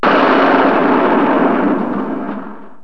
Tono di notifica Esplosione Ver. 2 per Cellulari Android o Iphone
EXPLOSE2.WAV